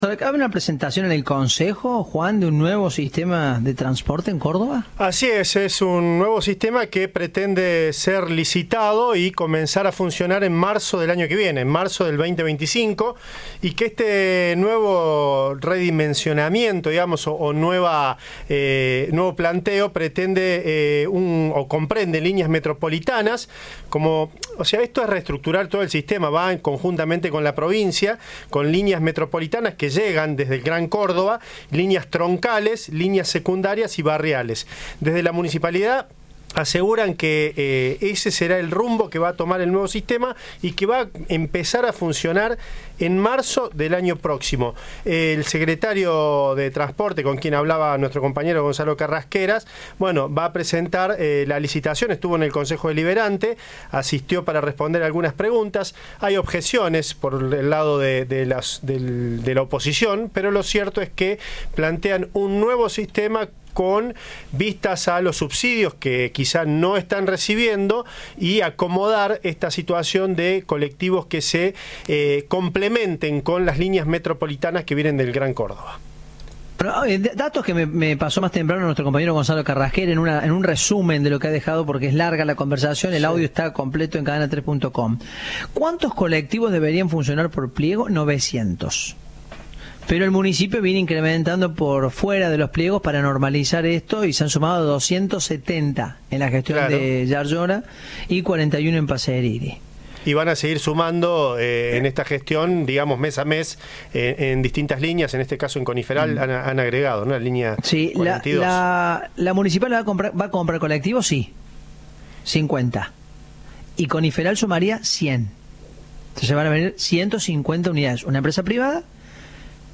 Audio. El secretario de Transporte repasó la crisis del sector en el Concejo Deliberante
El transporte urbano de la ciudad de Córdoba está en emergencia, según dijo Fernández, durante una sesión del Concejo Deliberante.